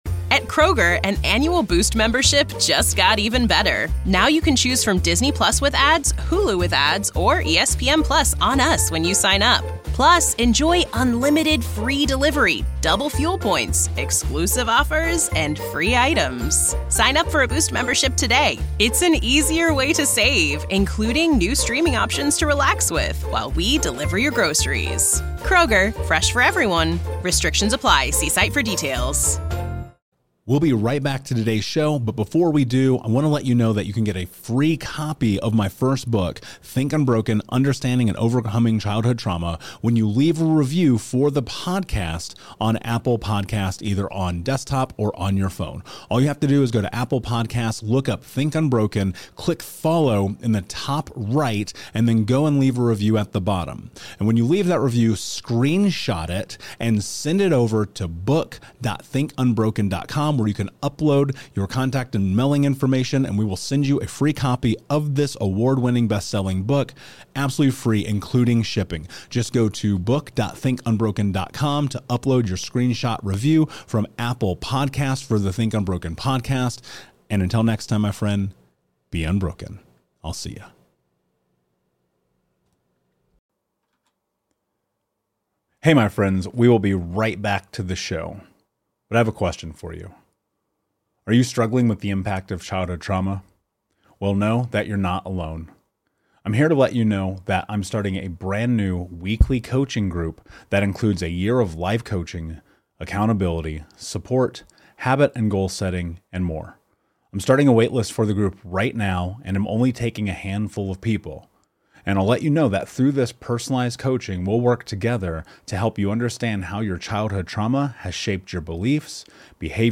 interviews Tom Bilyeu